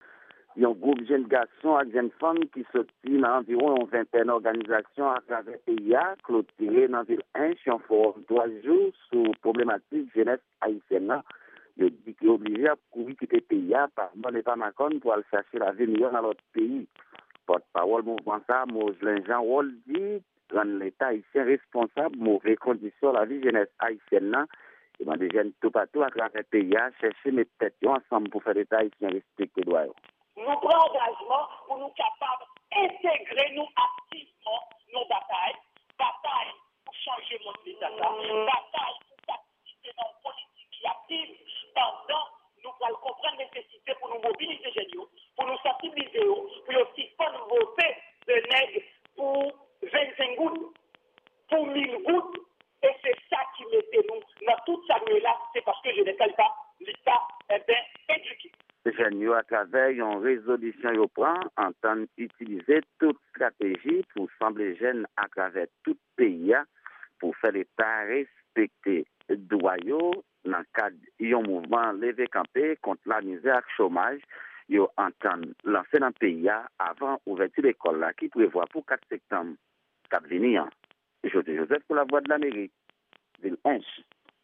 Yon repòtaj korespondan Lavwadlamerik